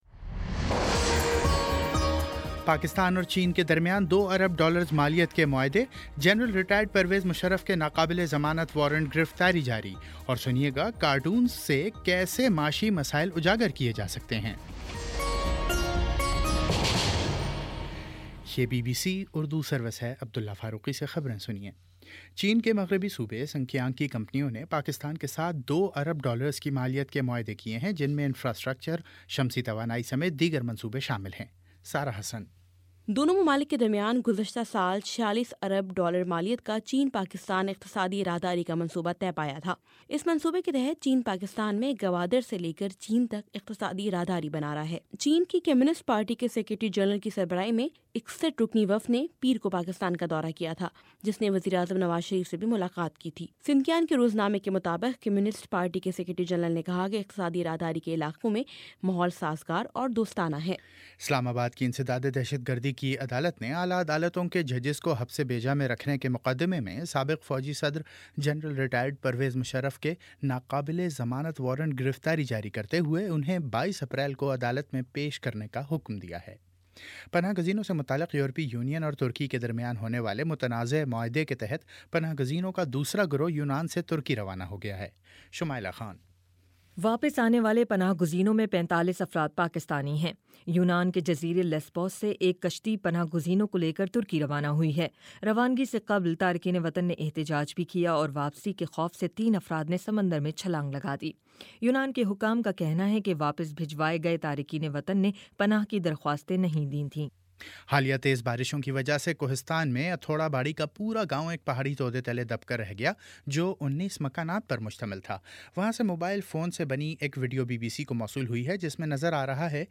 اپریل 08 : شام چھ بجے کا نیوز بُلیٹن